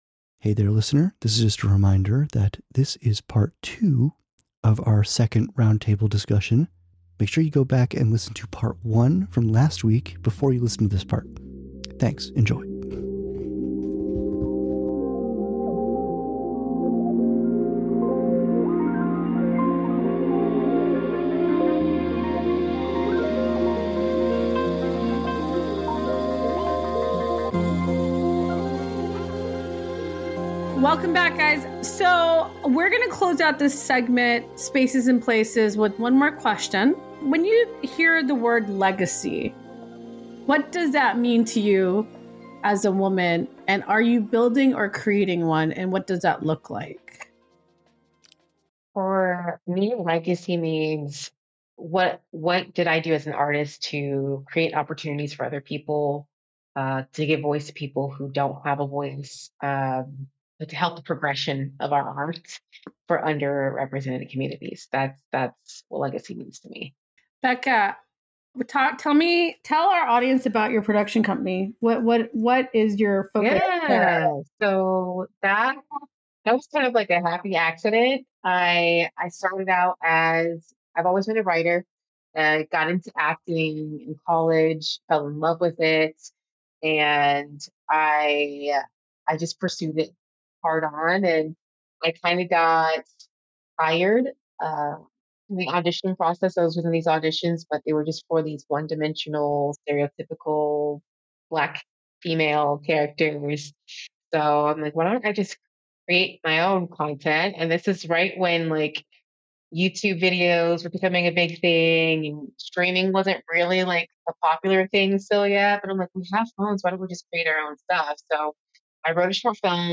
Beyond the Archetypes – A Round Table Series on Women’s Evolving Identities, Part 3